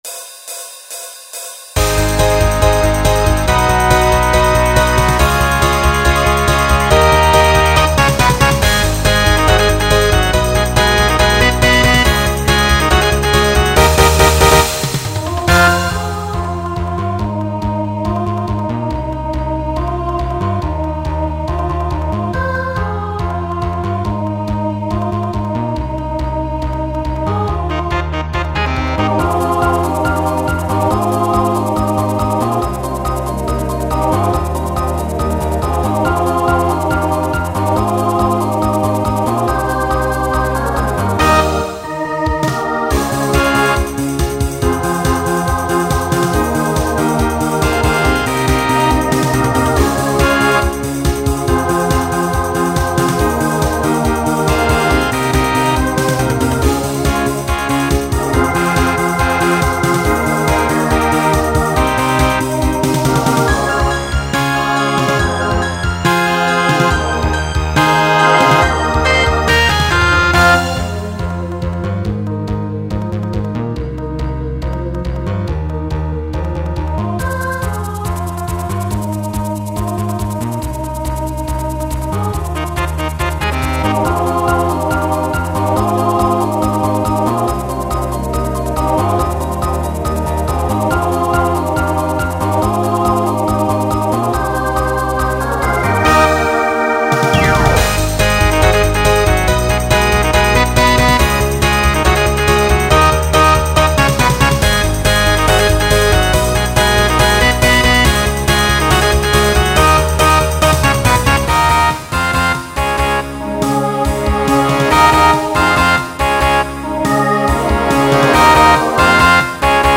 Pop/Dance , Rock Instrumental combo
Opener Voicing SATB